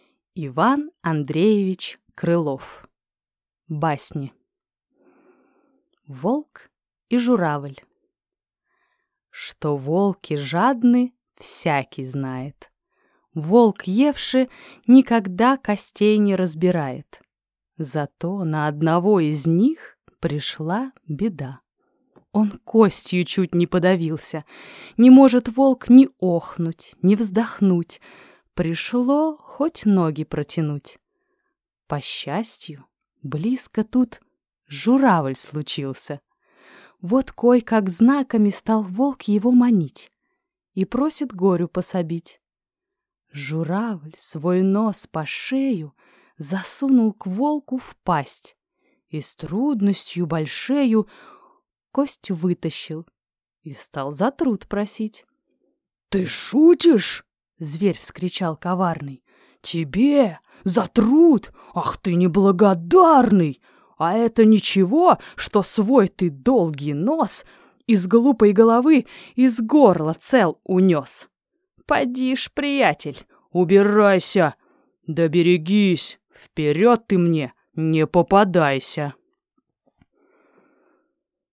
Аудиокнига Басни | Библиотека аудиокниг